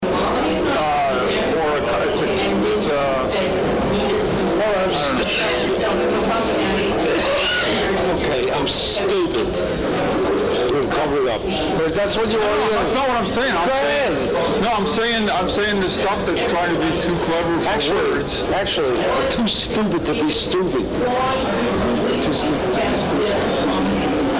SUICIDE PART TWO: EXCLUSIVE INTERVIEW WITH ALAN VEGA; "TOO STOOPID TO BE STOOPID!"